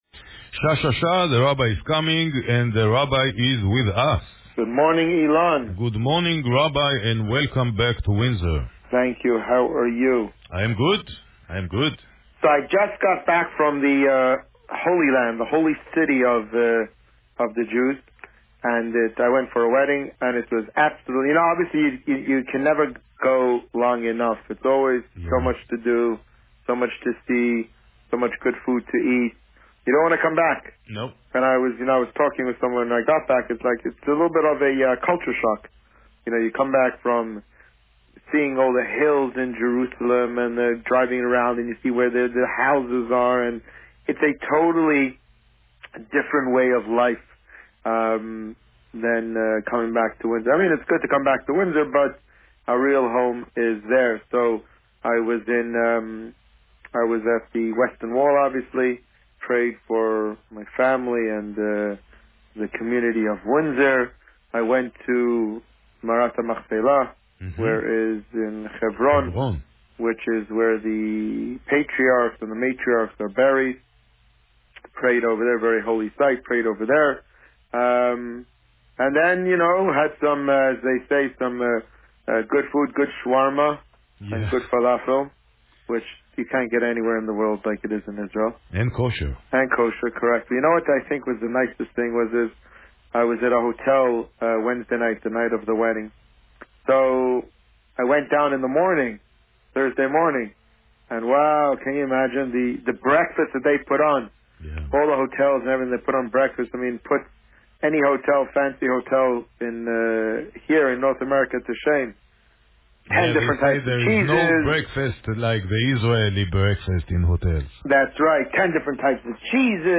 Today, the Rabbi spoke about his family's trip to Israel as well as Parsha Behaalotecha. Listen to the interview here.